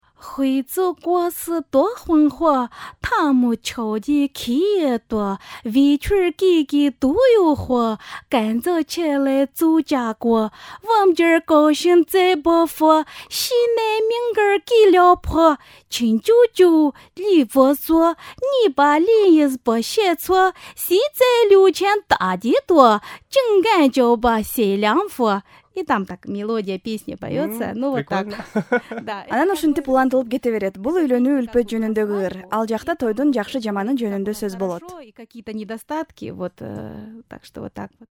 Dungan rap